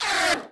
auto_flyby2.wav